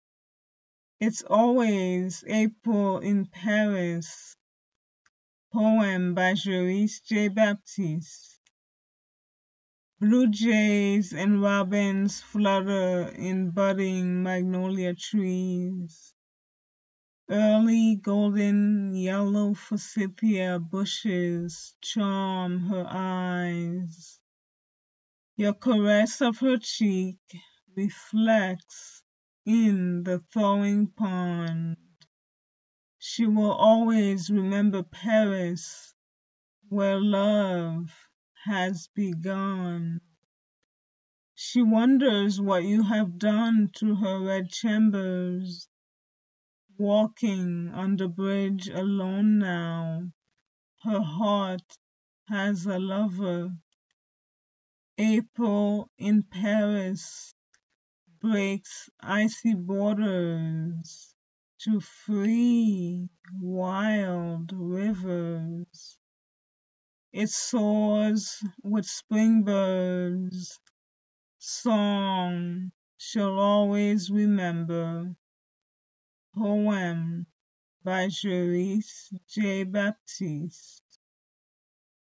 read her poem